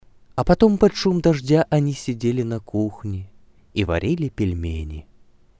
Вобщем... не получилось фокуса Хотел записать параллельно этот самый палмер, кабинет парой микрофонов и pod rack.